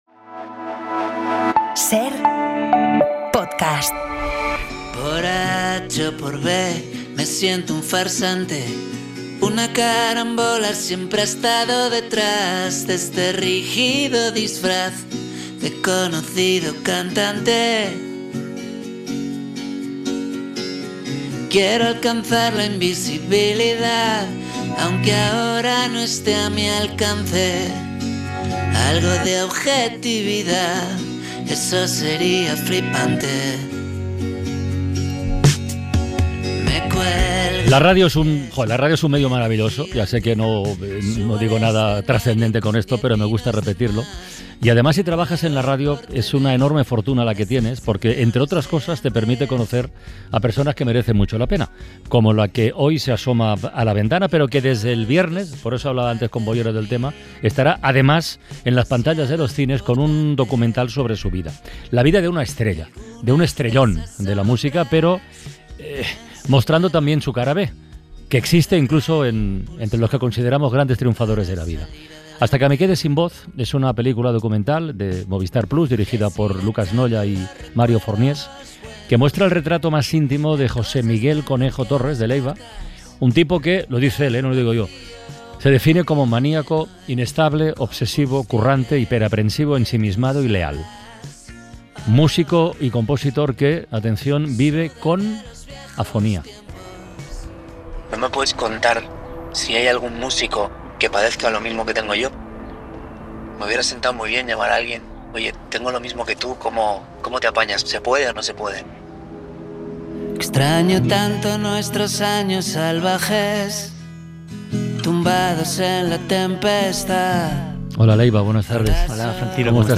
La Ventana | Entrevista a Leiva